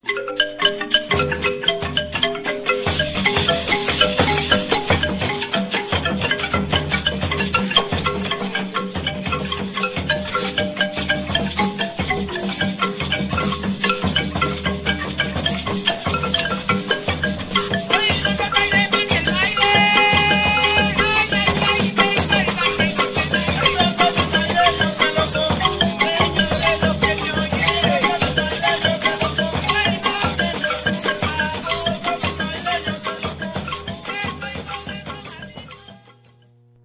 MARIMBA DE CHONTA
Xilófono de placas asentadas Código FUNDEF: 1-ECU-29
Afroecuatoriano, Costa del Pacífico.
Idiófono, de golpe, directo.
La ejecutan dos personas, cada una con dos baquetas (una en cada mano); forman parte de un conjunto con tambores Cununos, Bombos, Guasa y Canto, que intervienen, especialmente, en los bailes de la Bomba.
Ensamble: Voces, cununos; hembra y macho, bombos; hembra y macho guasá
Característica: El conjunto de marimba de chonta acompañ el canto y el baile en las principales fiestas de la provincia.
marimba_de_chonta.ra